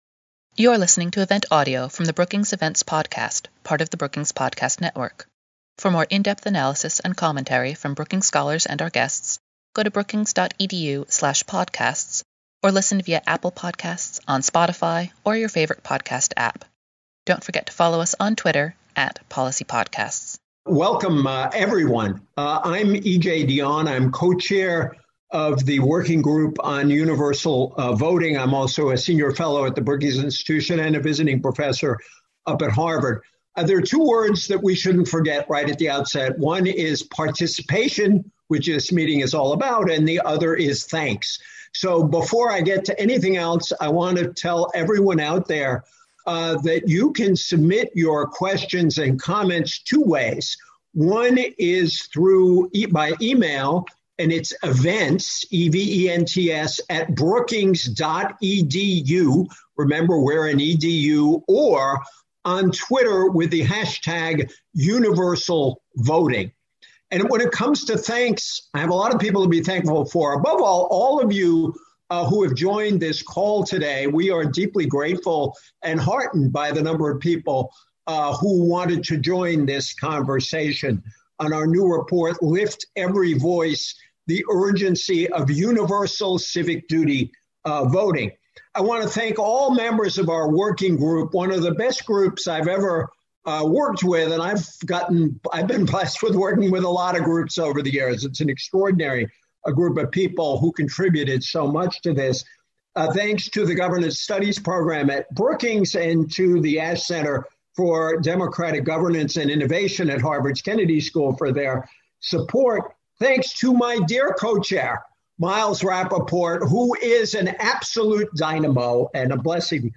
On July 20, Governance Studies at Brookings and the Ash Center for Democratic Governance and Innovation at Harvard Kennedy School cohosted a webinar to discuss key takeaways from the paper on civic duty voting.